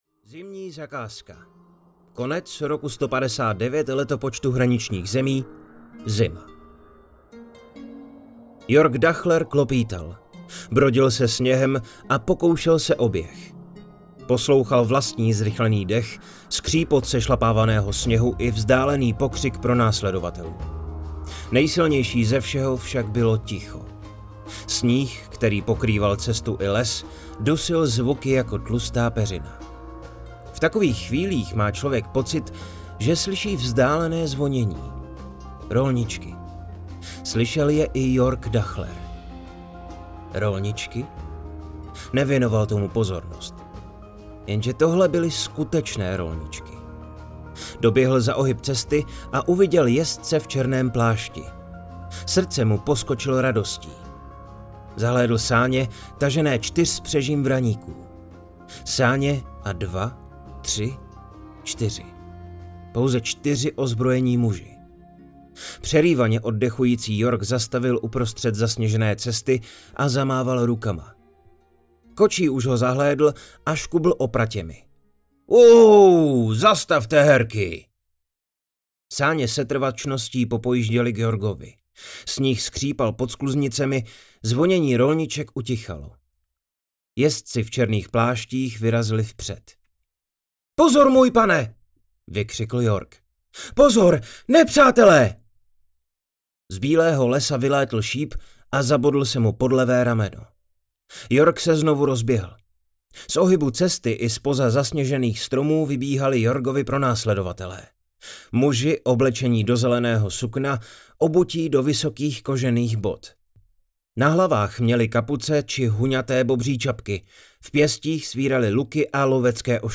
Nejlepší den pro umírání audiokniha
Ukázka z knihy